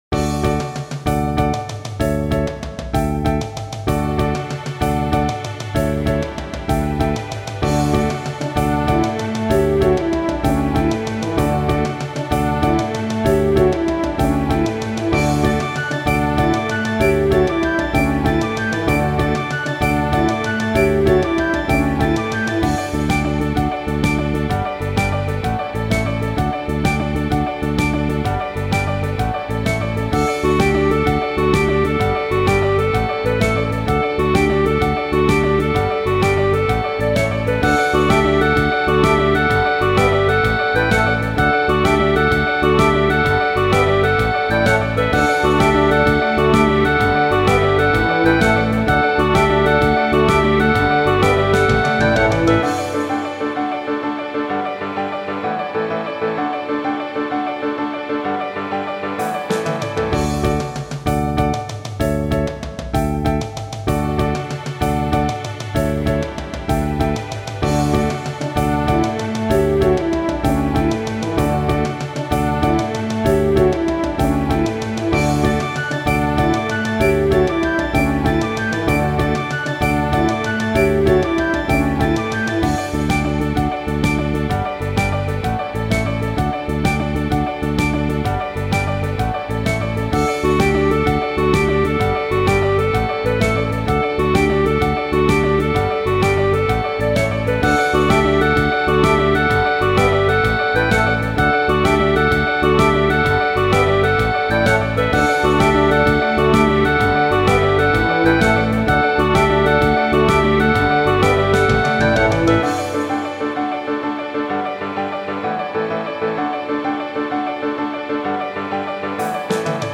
イメージ：達成 勝利   カテゴリ：RPG−街・村・日常